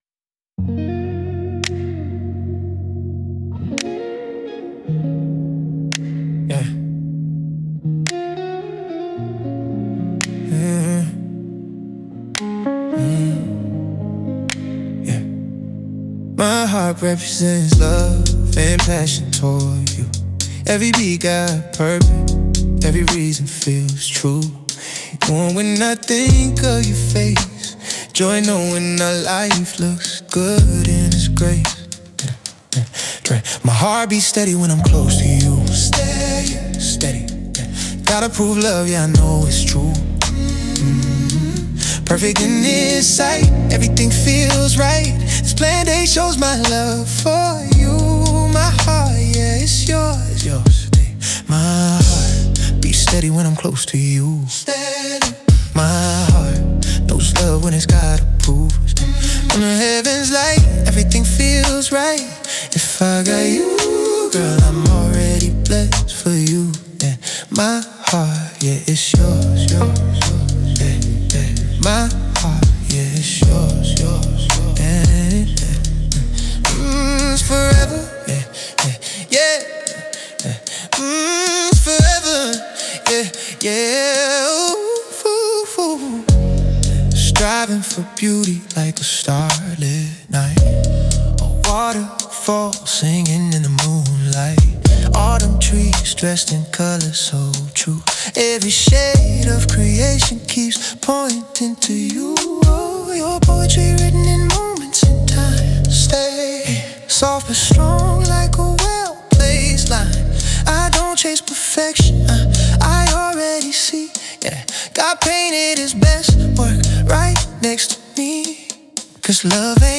• Gospel foundation with classic R&B warmth